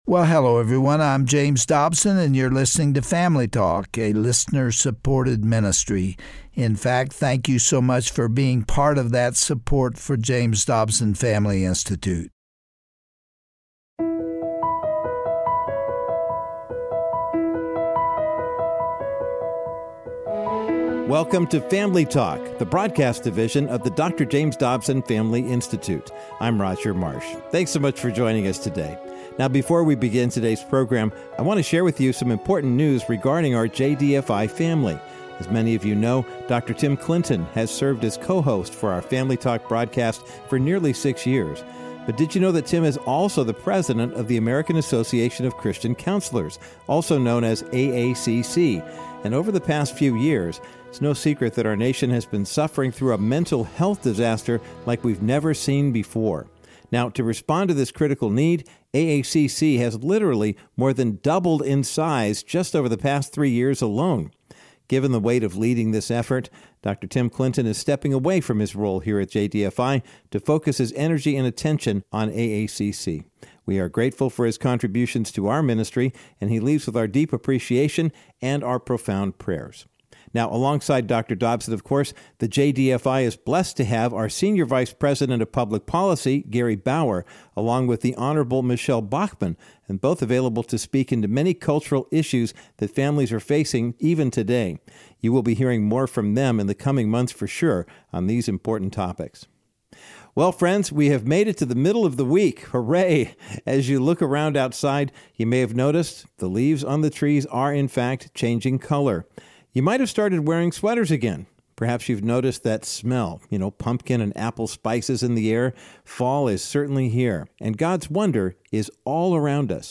On today’s classic edition of Family Talk, Dr. James Dobson welcomes to the program his wife, Shirley Dobson, and their long-time friend and colleague, Chuck Swindoll. They remind us that in times of uncertainty, our God is unchanging, ever-present, sovereign, and that we must continue to pray fervently for our nation.
Host Dr. James Dobson
Guest(s):Dr. Chuck Swindoll and Mrs. Shirley Dobson